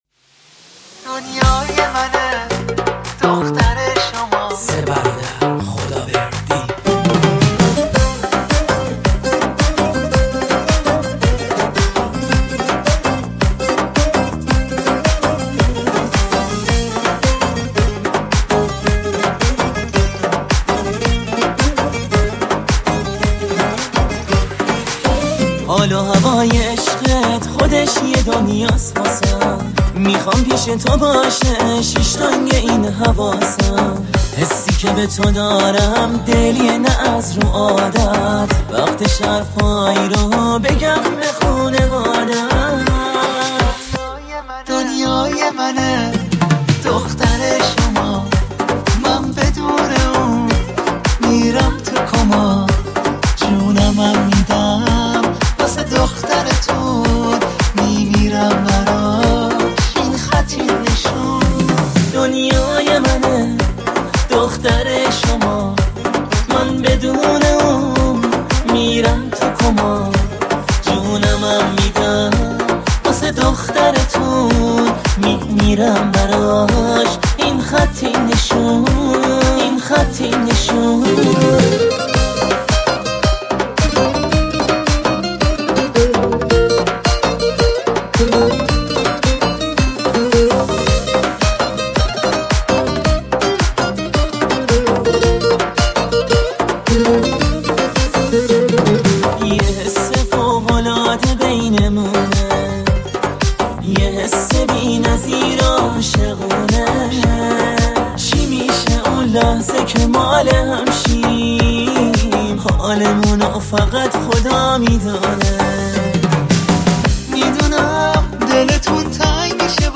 اهنگ شاد